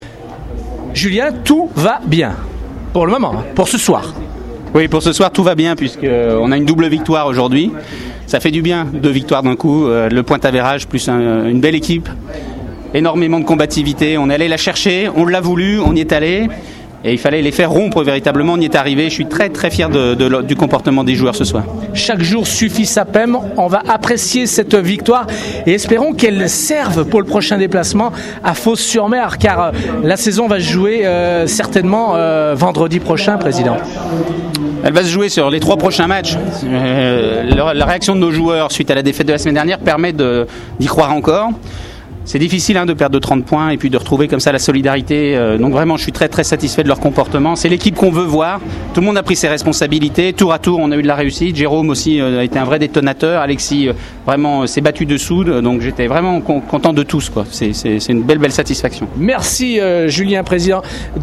réactions d’après-match